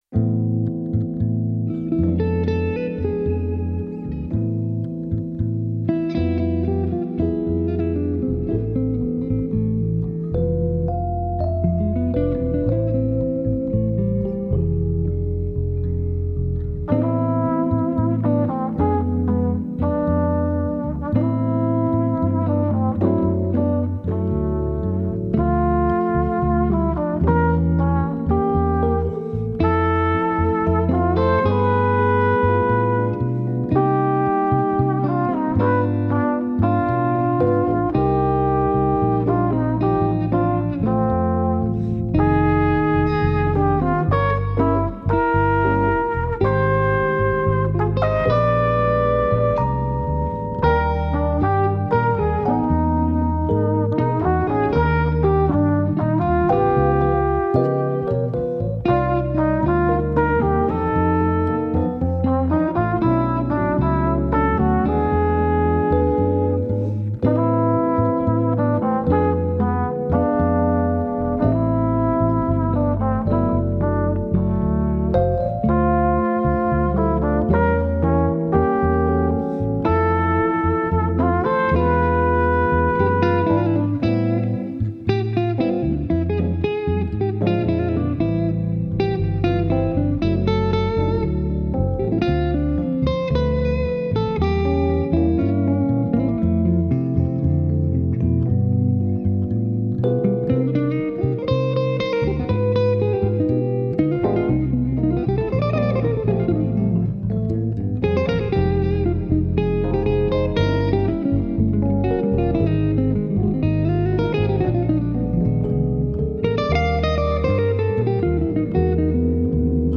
bass composition
keyboards
trumpet
Guitar